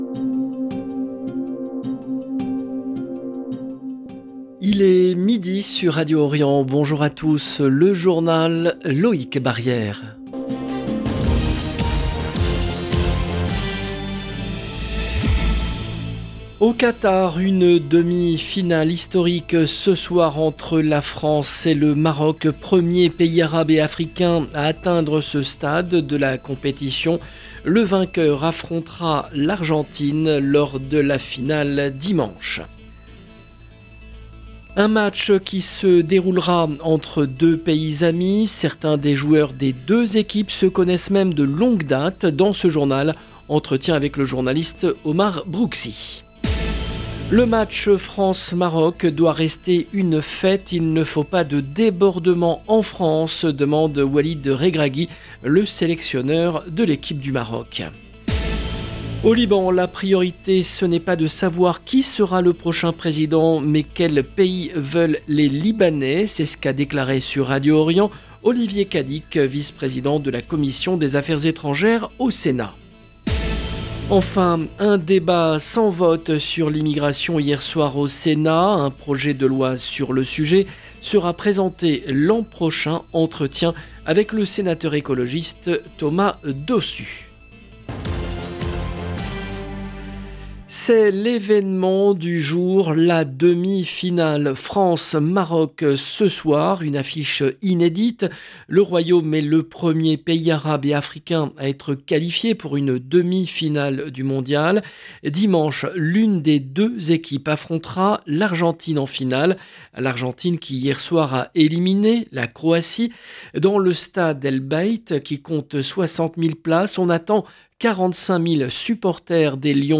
Entretien avec le sénateur écologiste Thomas Dossus. 0:00 18 min 8 sec